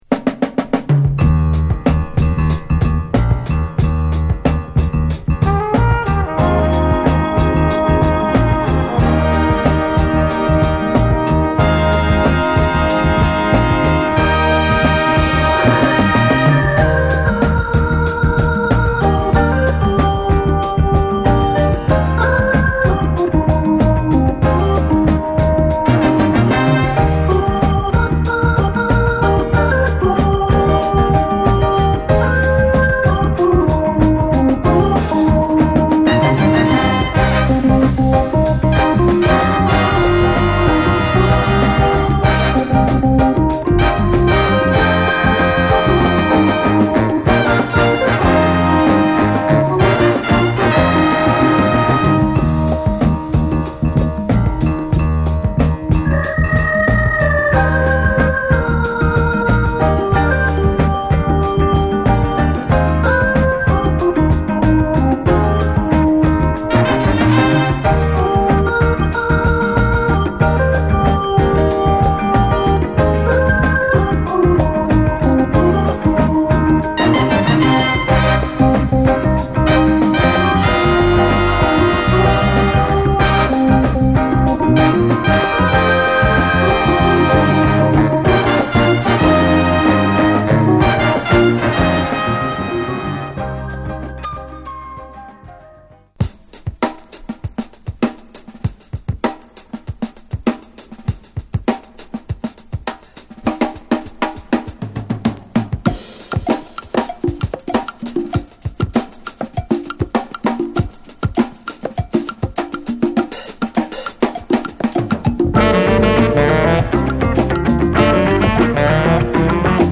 ＊試聴はA3「」→B1「」→B3「」です。